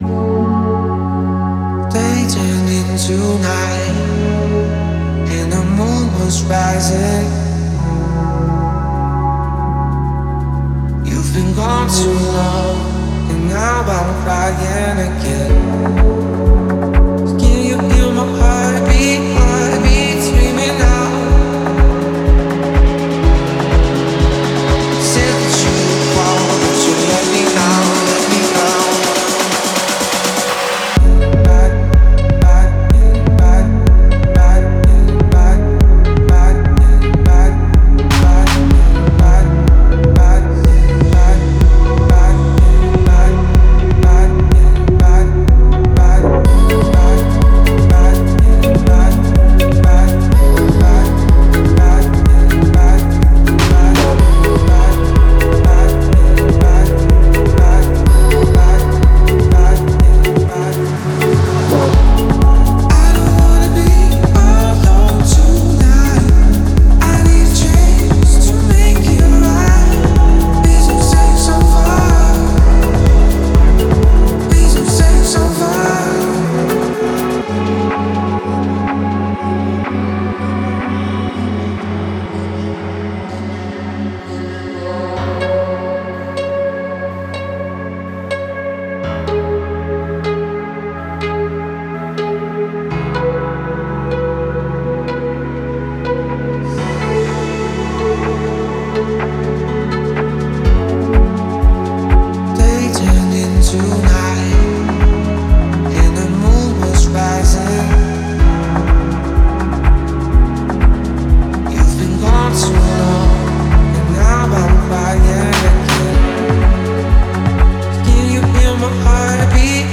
это трек в жанре поп-рок
Эмоциональное исполнение